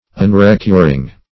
Unrecuring \Un`re*cur"ing\, a.